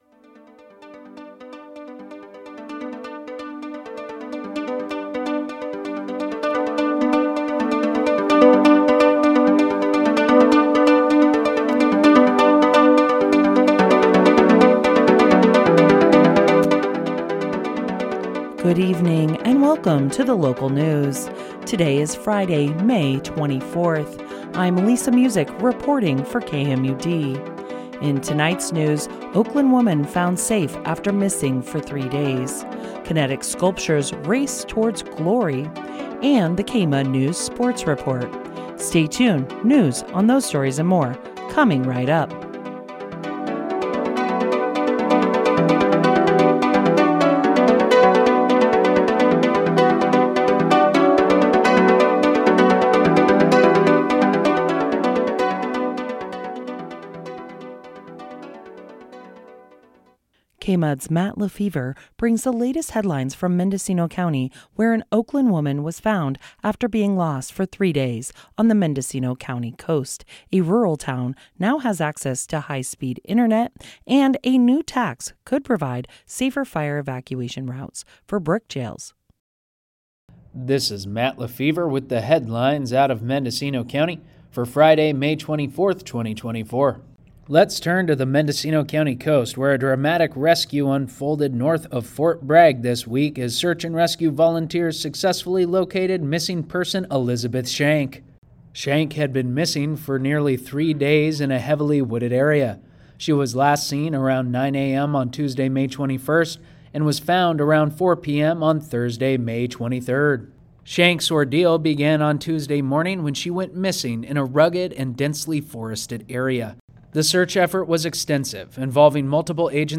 Local news.